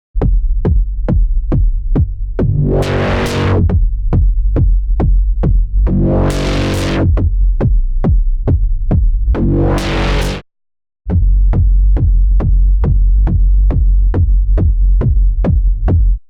Same technique, but utilizing the Filter to get a kick.